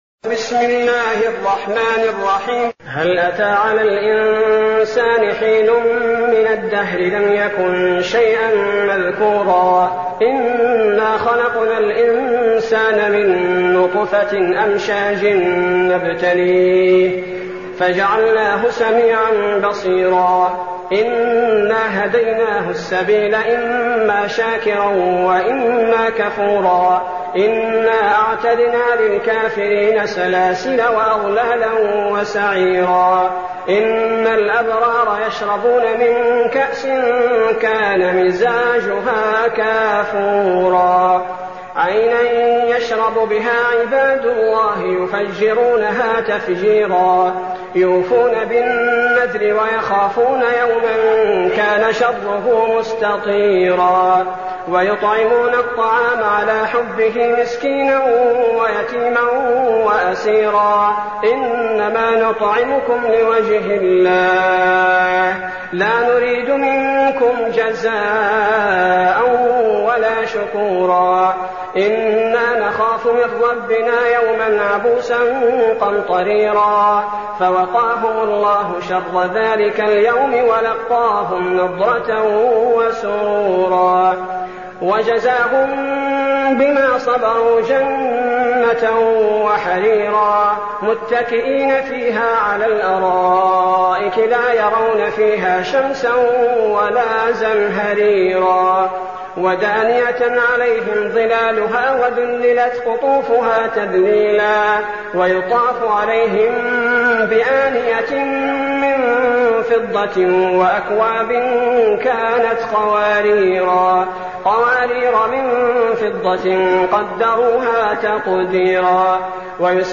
المكان: المسجد النبوي الشيخ: فضيلة الشيخ عبدالباري الثبيتي فضيلة الشيخ عبدالباري الثبيتي الإنسان